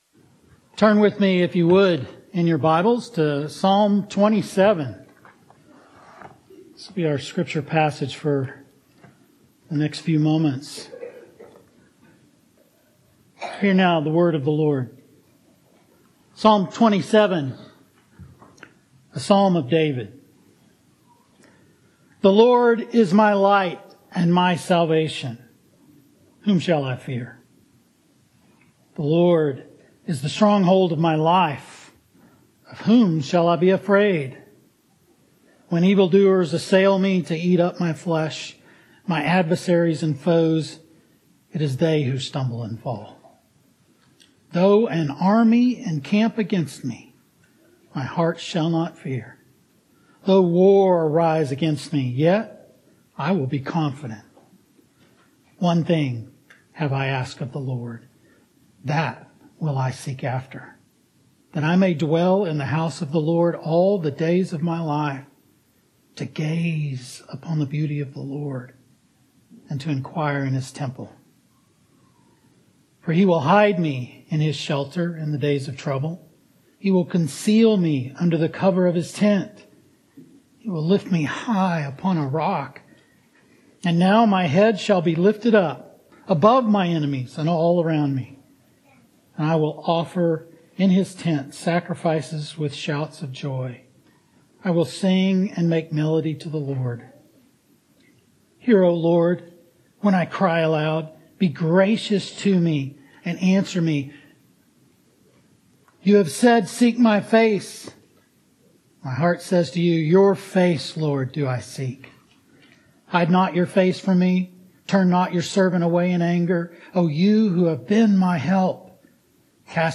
Sermons from Christ the King Presbyterian Church (PCA) in Austin, TX